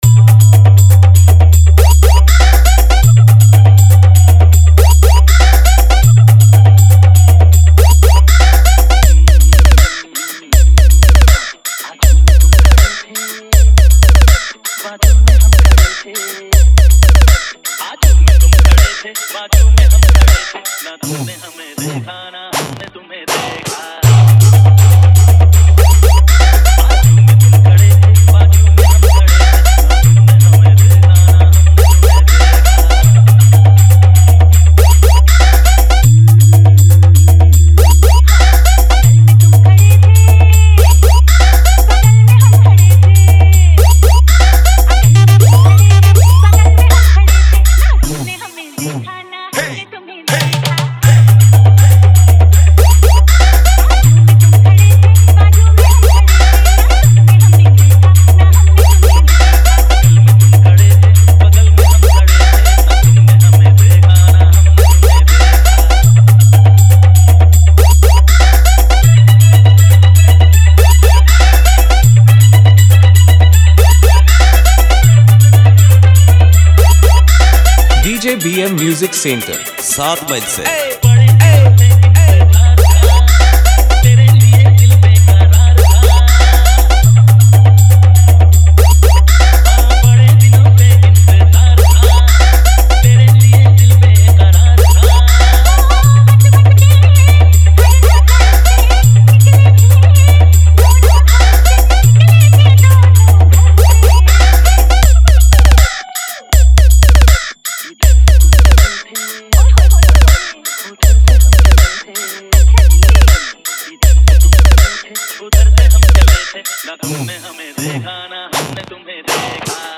New Style Competition 1 Step Long Humming Pop Bass Mix 2025